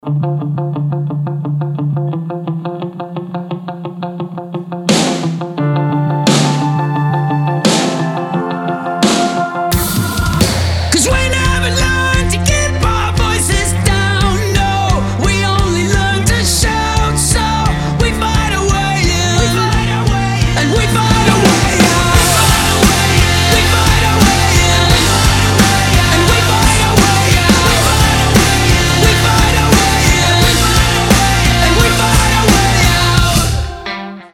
• Качество: 320, Stereo
громкие
красивый мужской голос
мотивирующие
Alternative Rock
indie rock